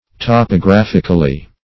Top`o*graph"ic*al*ly, adv.